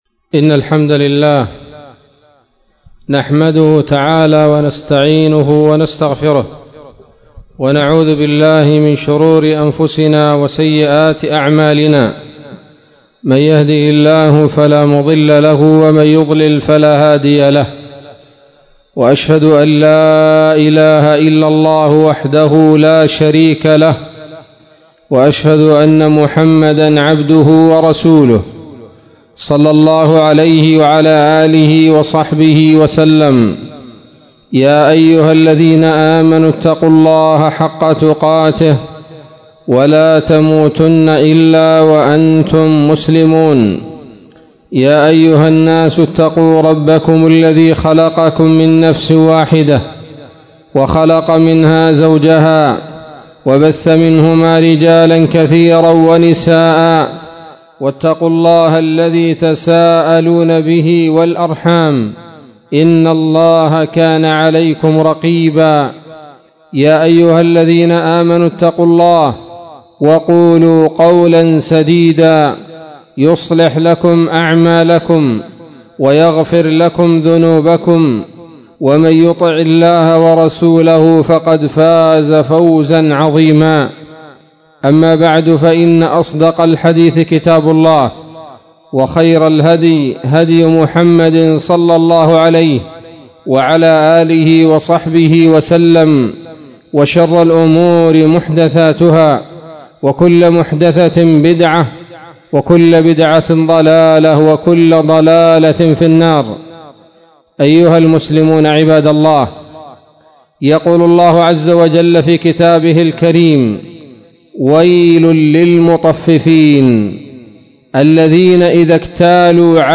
خطبة جمعة قيمة بعنوان:((قيام الساعة